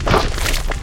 PixelPerfectionCE/assets/minecraft/sounds/mob/magmacube/big2.ogg at mc116